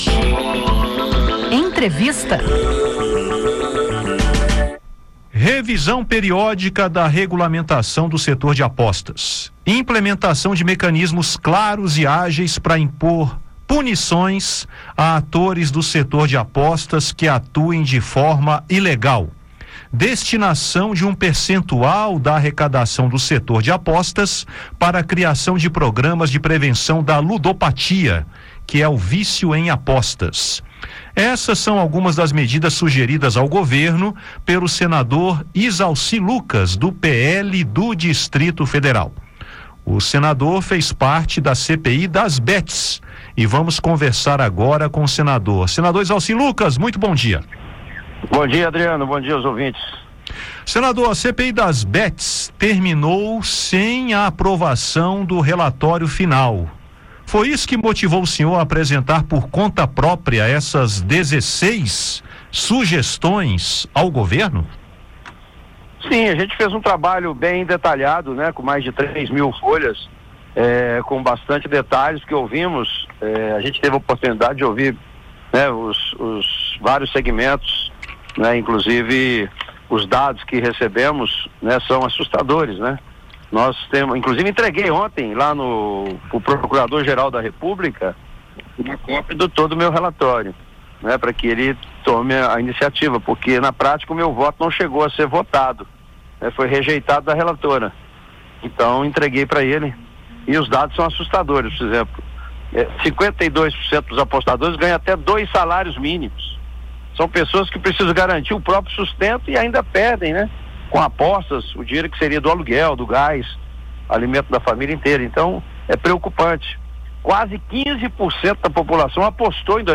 Em entrevista à Rádio Senado, Izalci Lucas destaca as medidas e as expectativas de diálogo sobre o assunto.